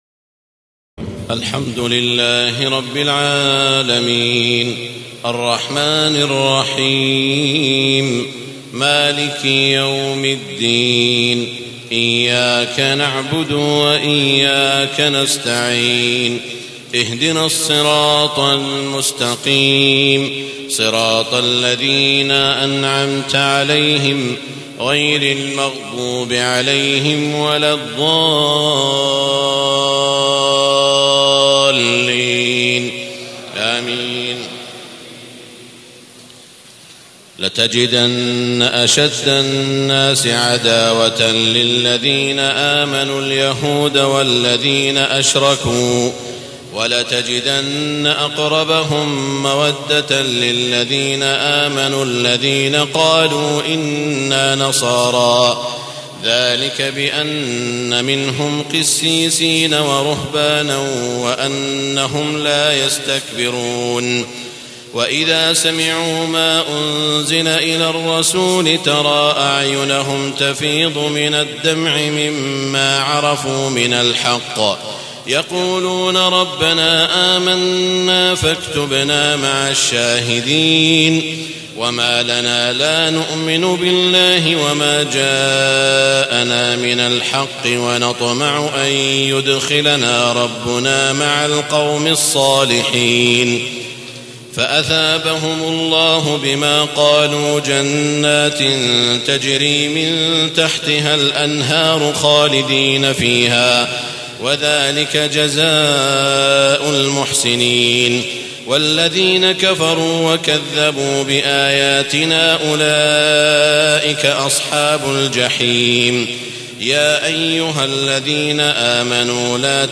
تهجد ليلة 27 رمضان 1432هـ من سورتي المائدة (82-120) و الأنعام (1-58) Tahajjud 27 st night Ramadan 1432H from Surah AlMa'idah and Al-An’aam > تراويح الحرم المكي عام 1432 🕋 > التراويح - تلاوات الحرمين